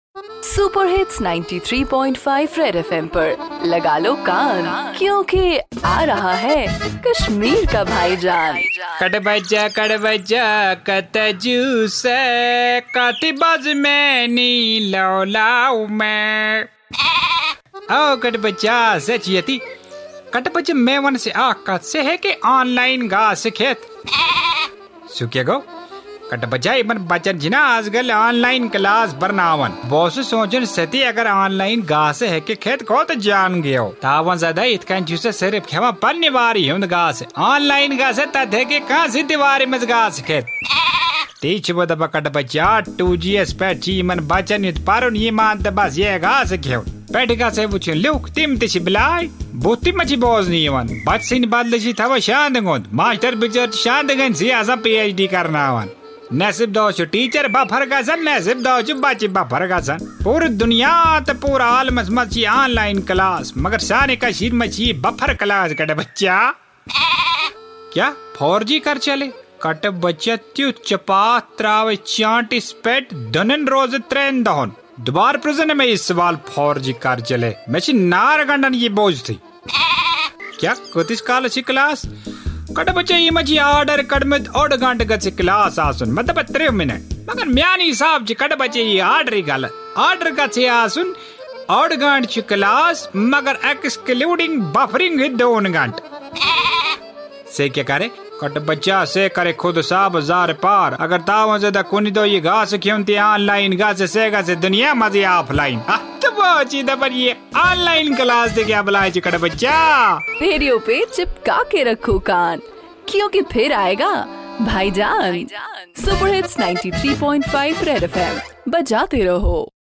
Bhaijaan the ultimate dose of comedy in Kashmir which is high on satire and humor